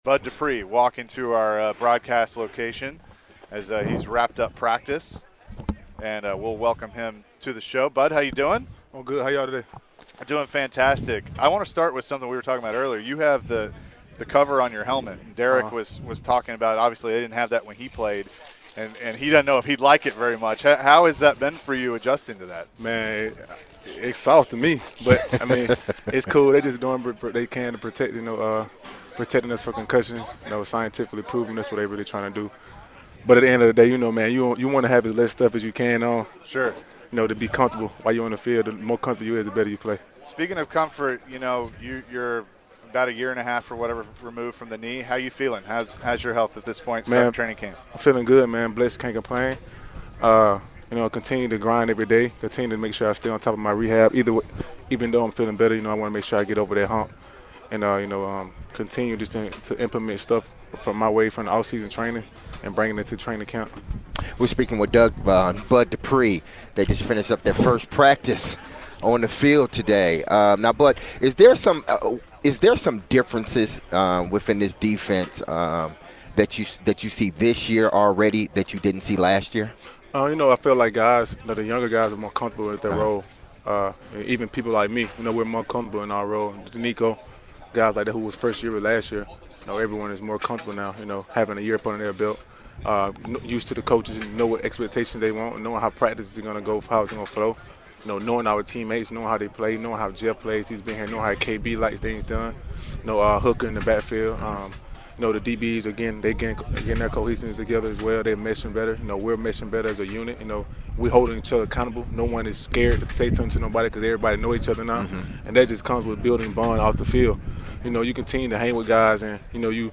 Bud Dupree interview (7-27-22)